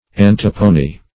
Search Result for " antepone" : The Collaborative International Dictionary of English v.0.48: Antepone \An"te*pone\, v. t. [L. anteponere.] To put before; to prefer.